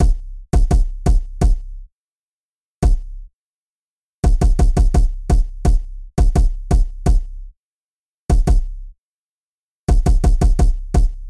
Tag: 170 bpm Trap Loops Drum Loops 1.90 MB wav Key : A